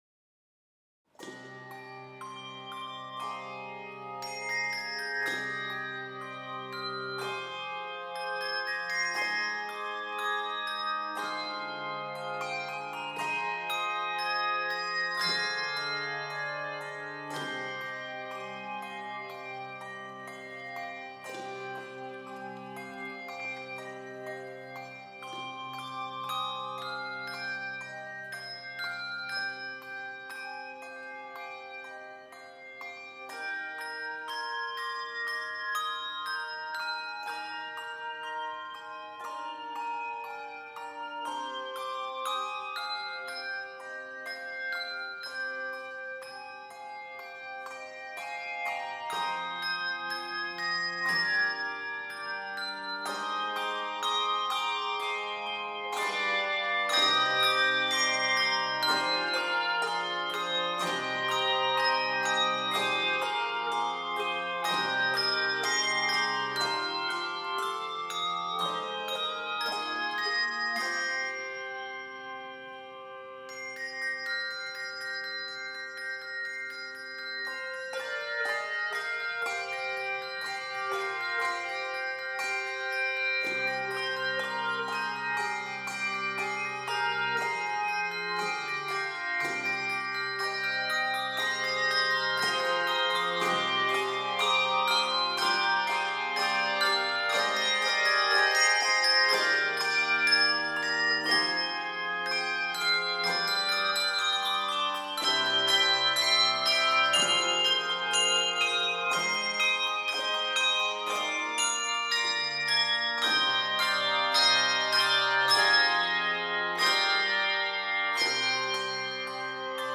hauntingly beautiful arrangement